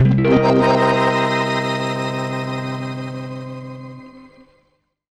GUITARFX 2-R.wav